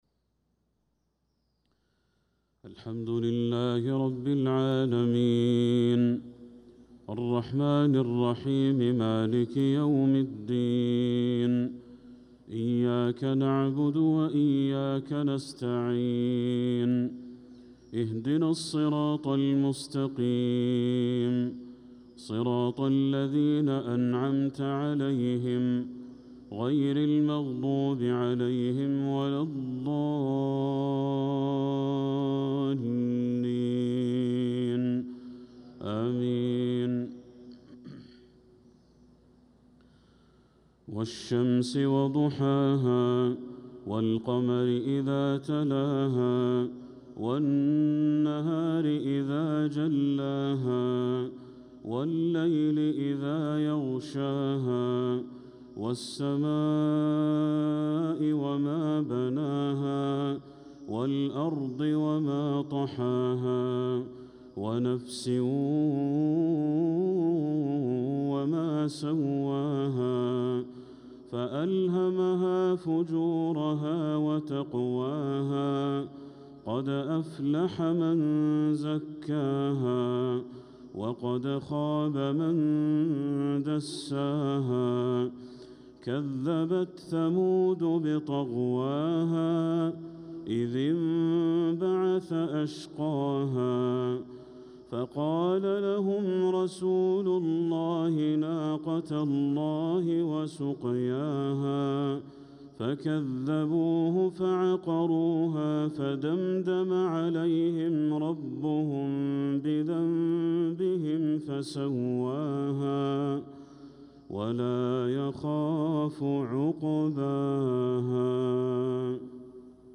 صلاة المغرب للقارئ بدر التركي 6 ربيع الآخر 1446 هـ
تِلَاوَات الْحَرَمَيْن .